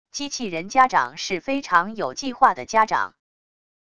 机器人家长是非常有计划的家长wav音频生成系统WAV Audio Player